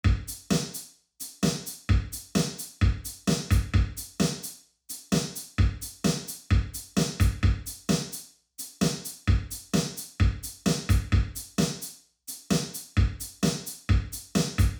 Chamber Reverb klingt natürlich, warm und dicht, eignet sich besonders für Drums und gibt ihnen einen organischen Raumklang.
Im folgenden Soundbeispiel hören Sie ein Drum-Set mit einem Kammerhall:
Drums-Chamber-Hall.mp3